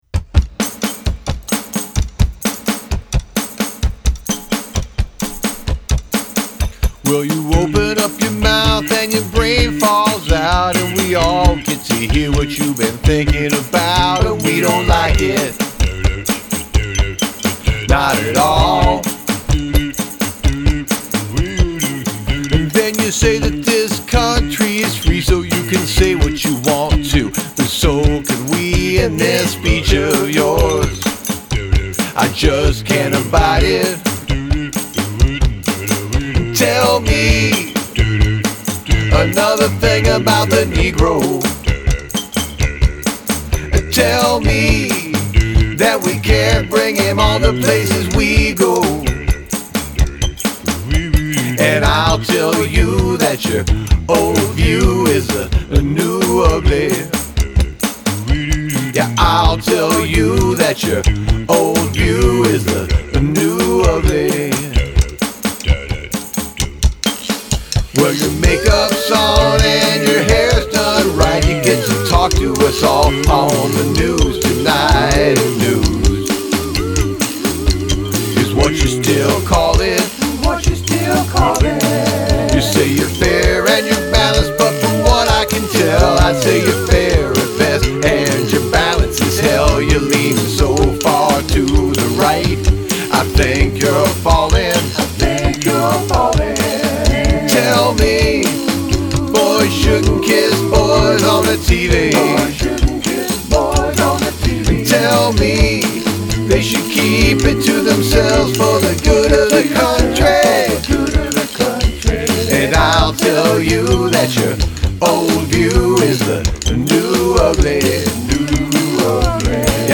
Only Percussion and Voice (no pianos allowed)